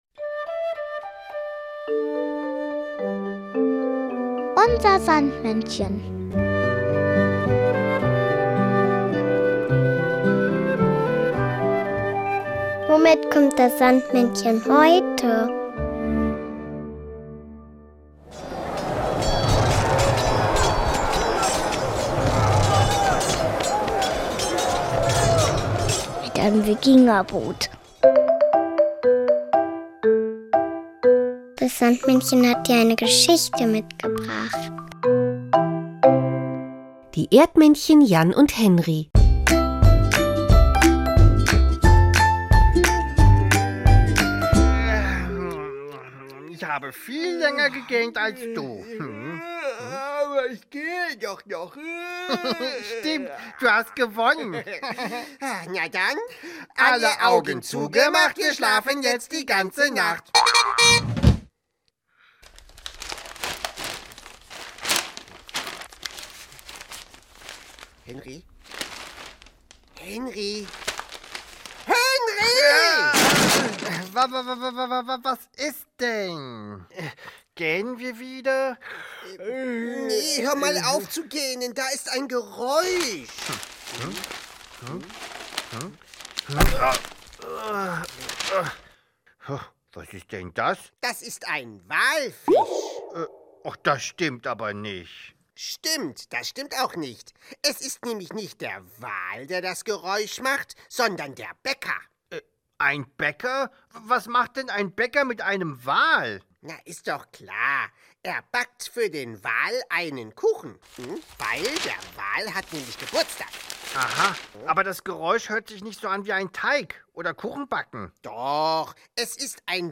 nur diese Geschichte mitgebracht, sondern auch noch das Kinderlied